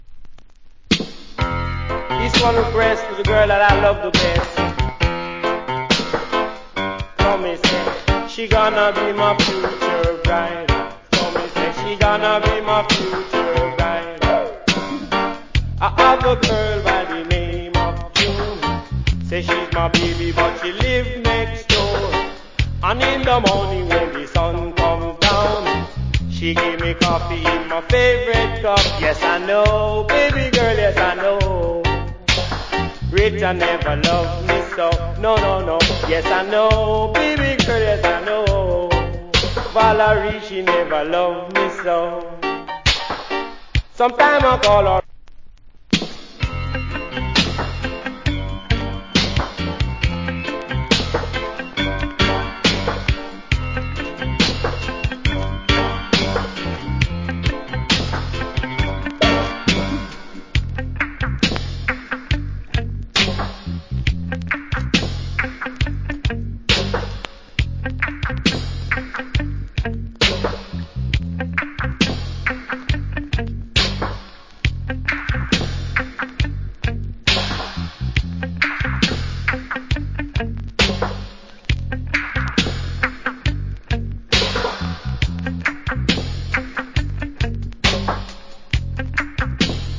Nice DJ.